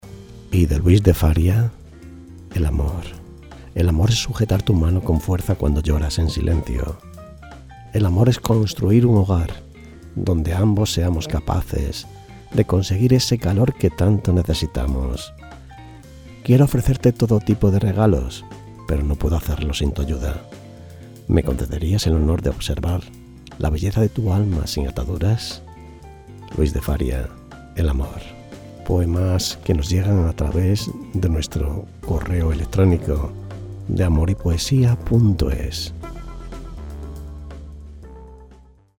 Poemas románticos recitados.
Poemas románticos a los que nosotros le ponemos voz y sentimiento.